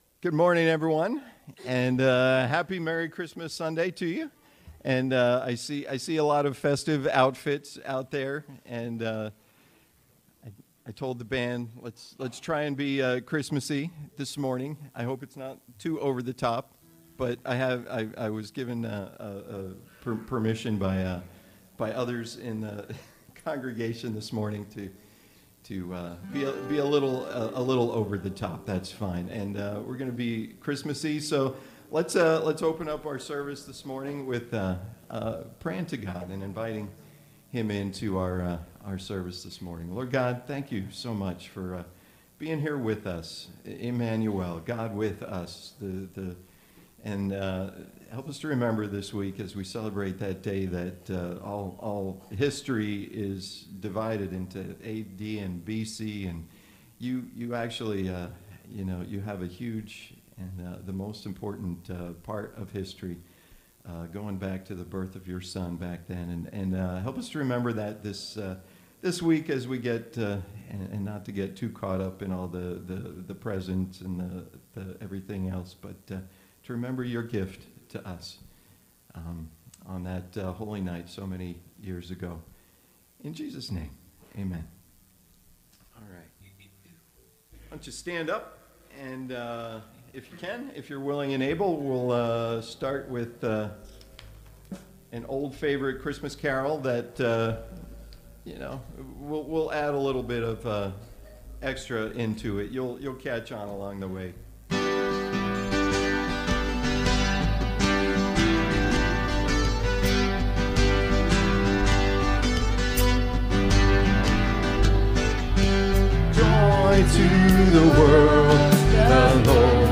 CCC Sermons Passage: Luke 1:26-38, 46-56 Youversion Event My next step with God this week is to…